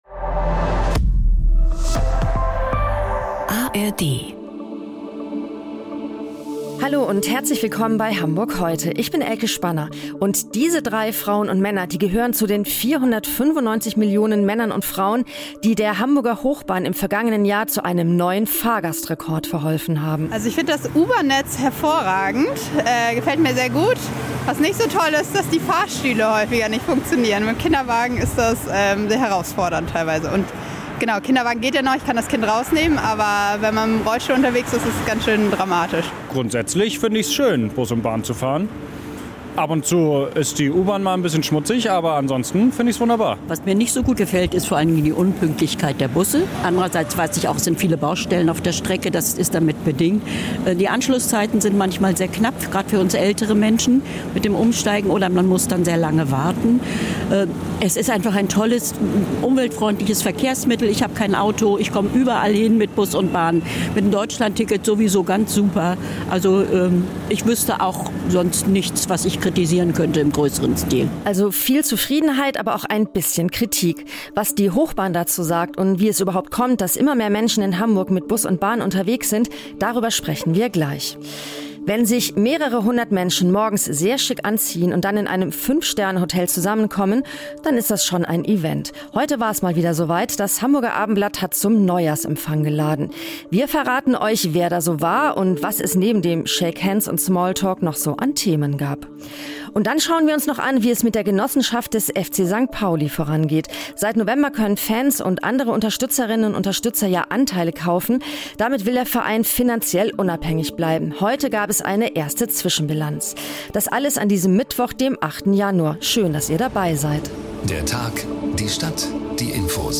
… continue reading 497 episodi # NDR 90,3 # NDR 90 # Tägliche Nachrichten # Nachrichten # St Pauli